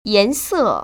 [yánsè] 옌써